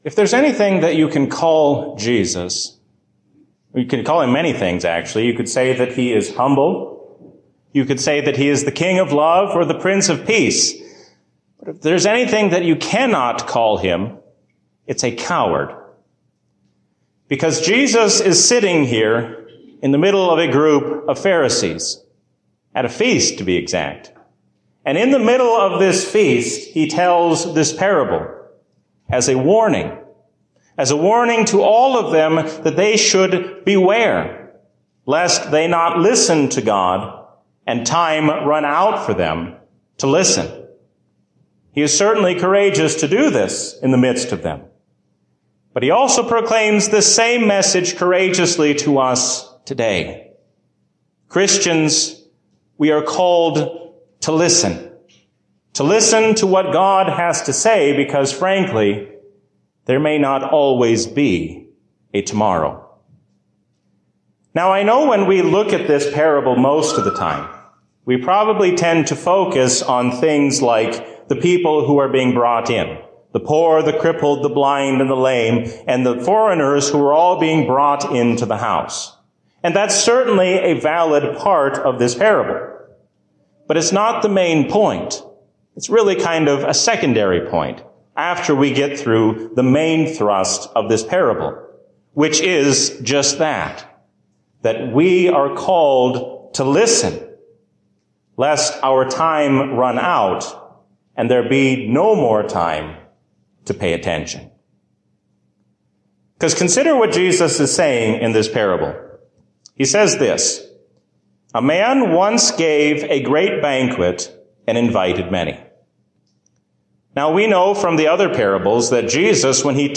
A sermon from the season "Trinity 2024." Doing what God says means following after Him without making excuses.